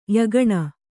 ♪ yagaṇa